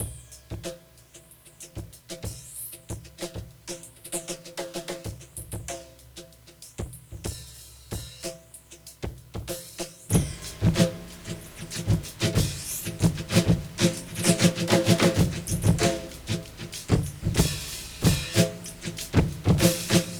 Breakbeat 2
Submerge 095bpm